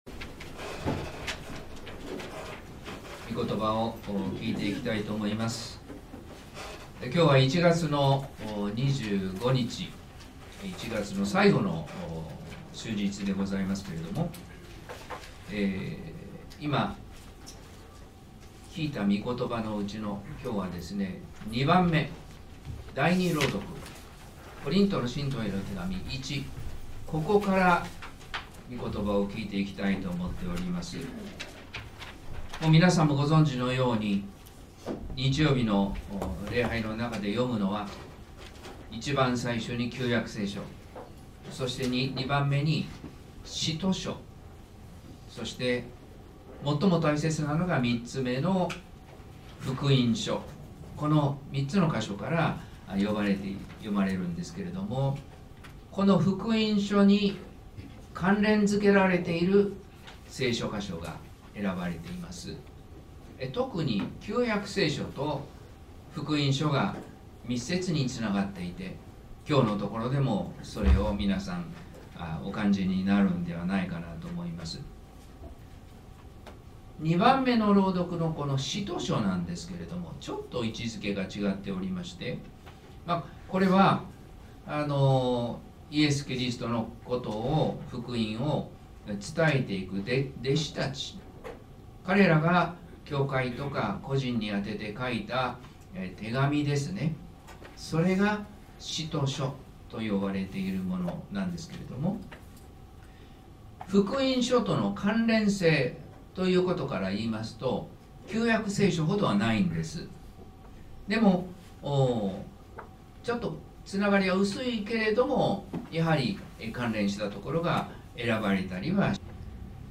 説教「十字架が福音です」（音声版） | 日本福音ルーテル市ヶ谷教会